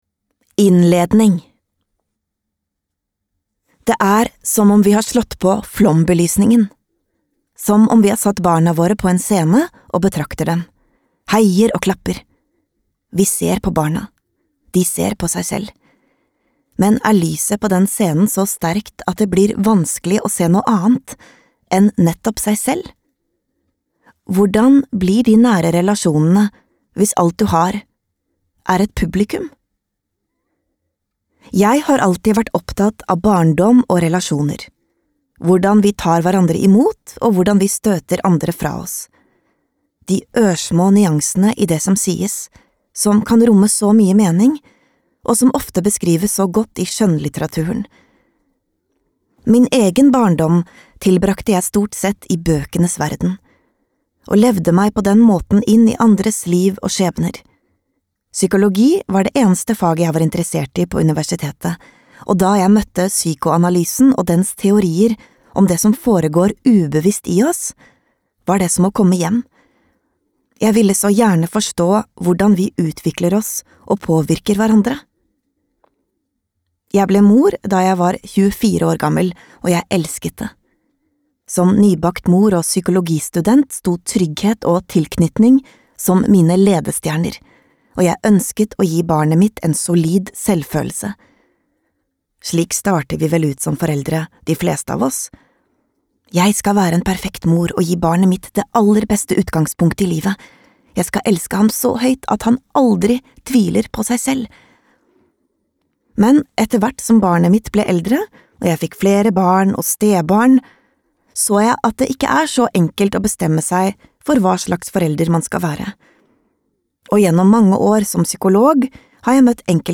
lydbok